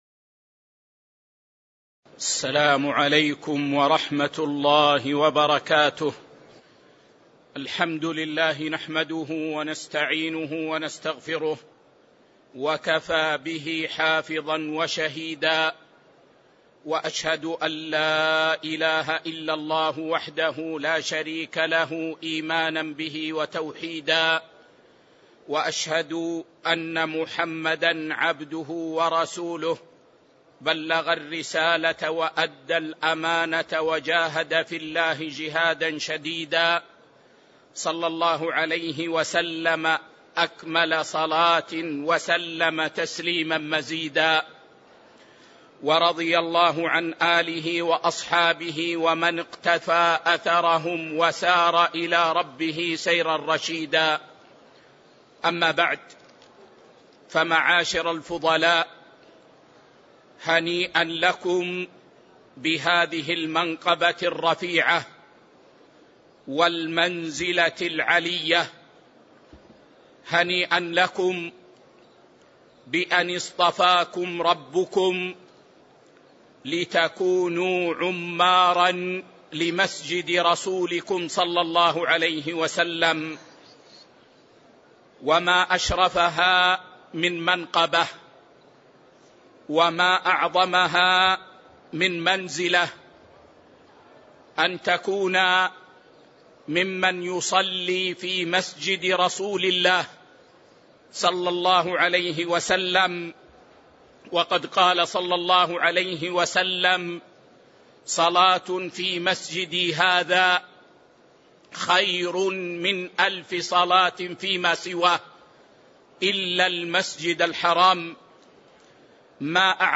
تاريخ النشر ٧ ذو القعدة ١٤٤٤ هـ المكان: المسجد النبوي الشيخ